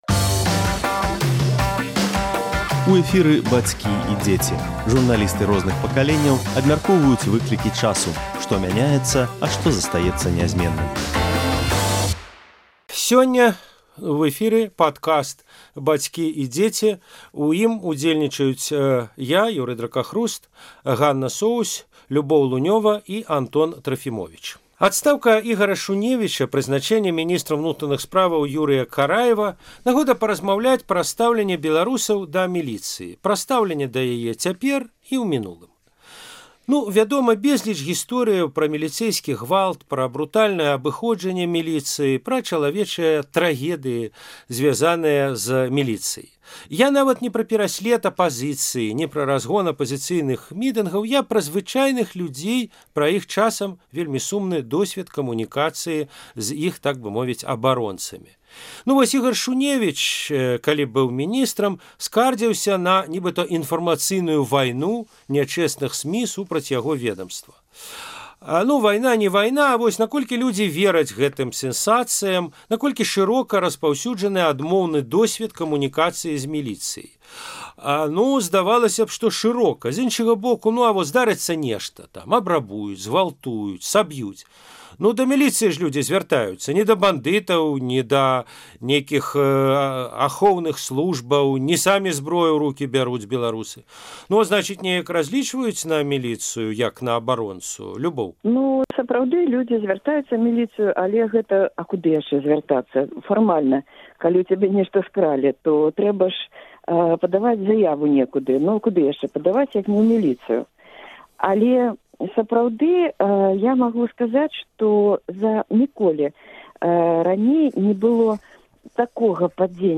Як мянялася стаўленьне людзей да міліцыі за савецкім часам і ў розныя пэрыяды незалежнай Беларусі? Абмяркоўваюць журналісты